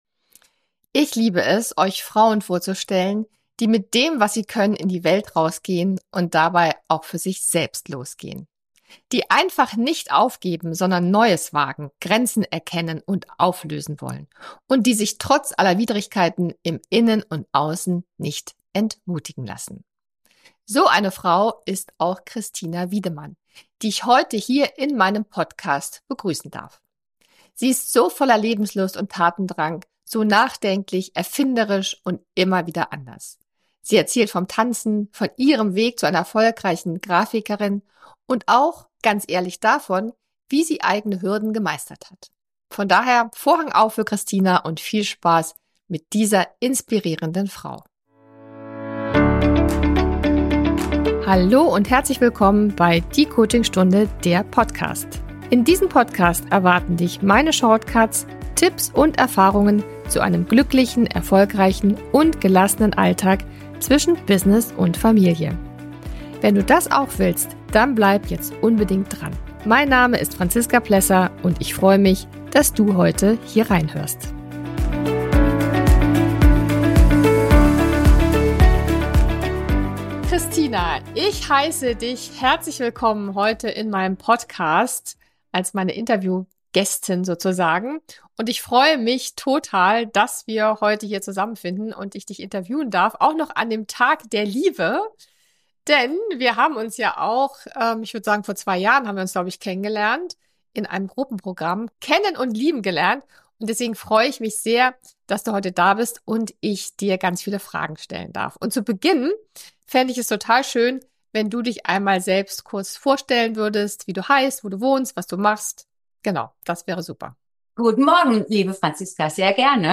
#131 Design, Energie, Bewegung. Mein Weg als Grafikerin & Tänzerin | Im Interview mit